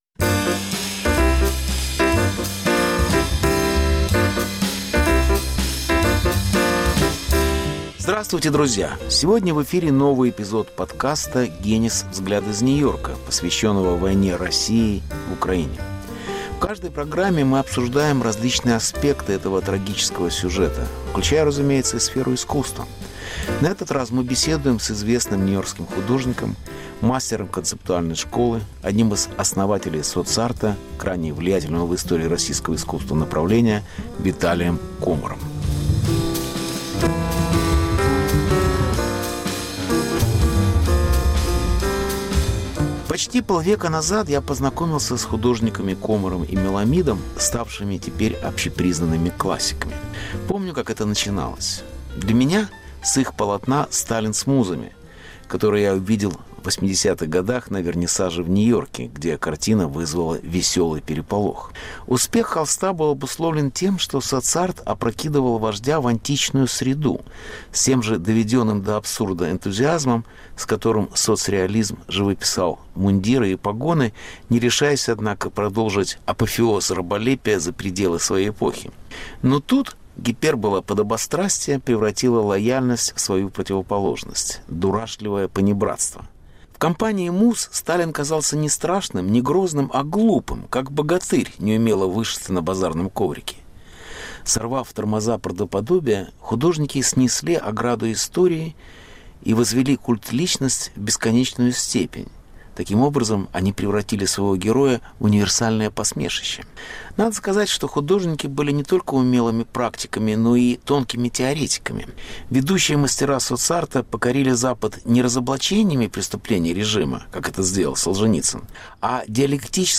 Ведущие мастера Соц-арта покорили Запад не разоблачениями преступлений режима, как это сделал Солженицын, а диалектической трактовкой советского опыта. Беседа с художником Виталием Комаром. Повтор эфира от 13 ноября 2022 года.